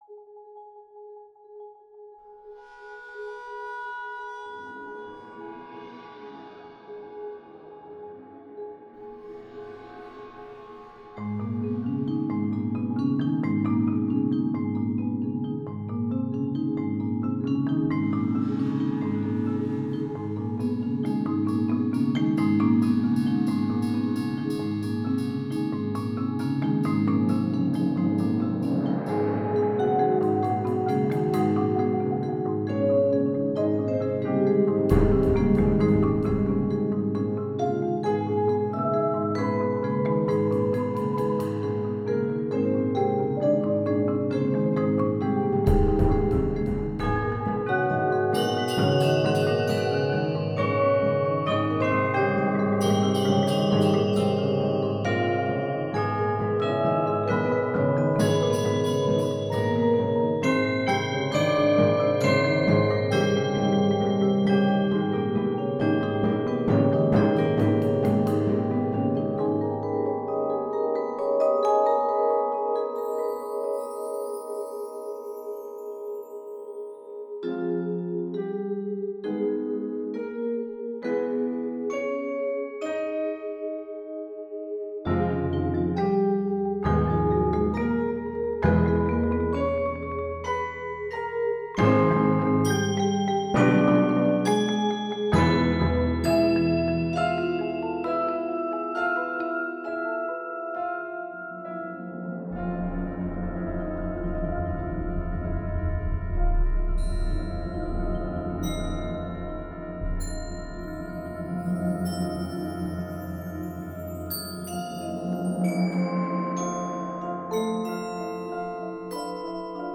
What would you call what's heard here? Voicing: 16 Percussion